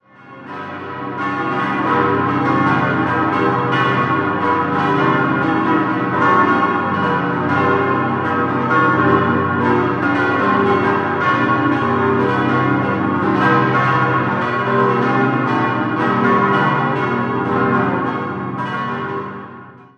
In den Jahren 1925 bis 1927 wurde die mächtige katholische Pfarrkirche Herz Jesu errichtet. 6-stimmiges Geläut: gis°-h°-cis'-dis'-fis'-gis' Die große Glocke wurde 1939, die fünf kleineren bereits 1931 von der Gießerei Rüetschi in Aarau gegossen.